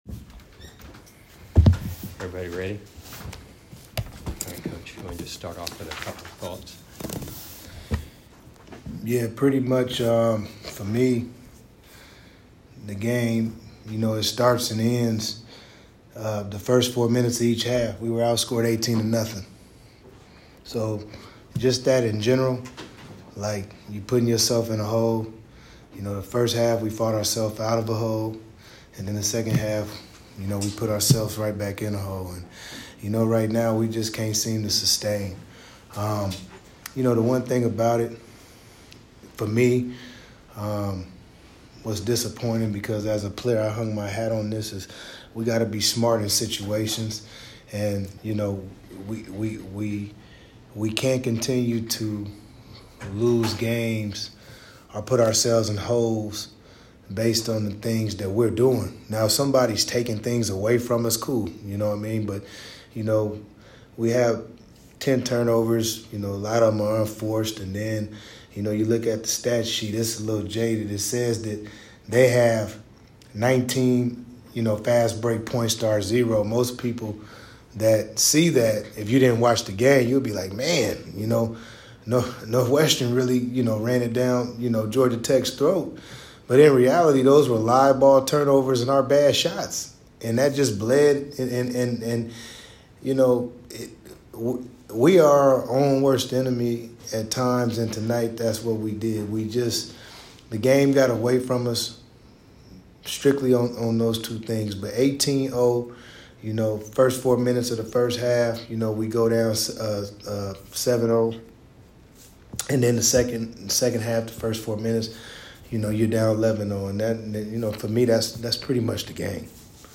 STOUDAMIRE POST-GAME AUDIO